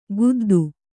♪ guddu